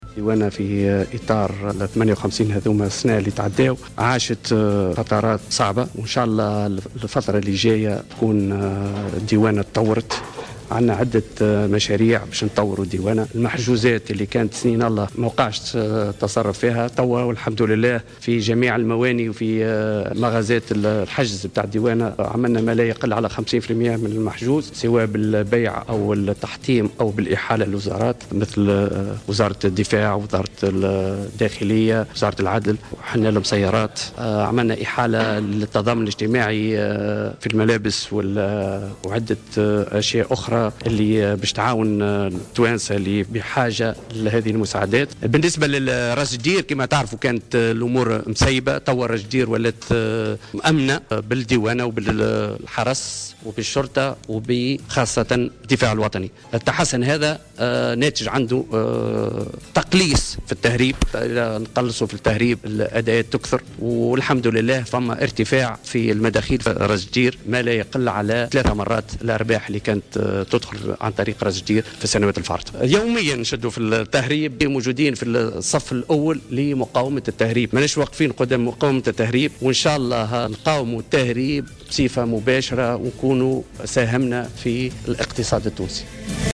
Kamel Ben Nasr, directeur général de la Douane tunisienne a déclaré ce samedi 6 décembre 2014, que les revenus des saisies de la Douane à Ras Jdir ont triplé par rapport aux résultats enregistrés lors des années précédentes.